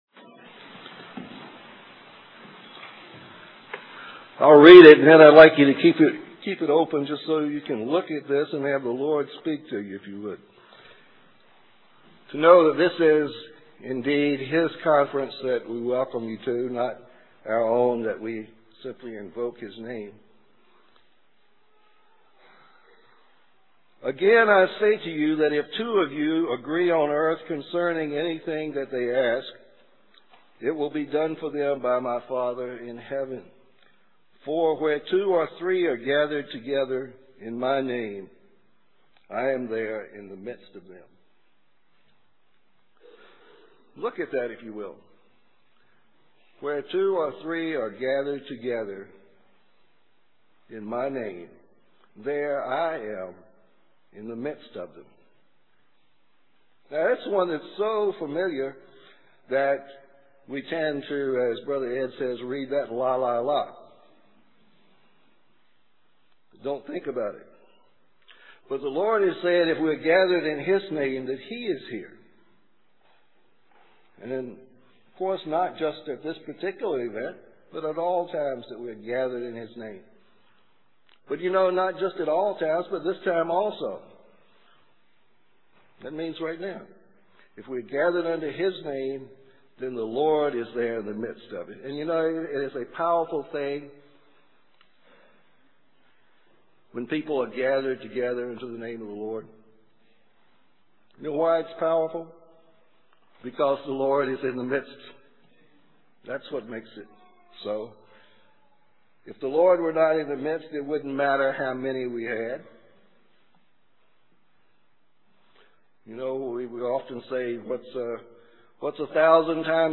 Introductory Remarks -- 2003 Christian Family Conference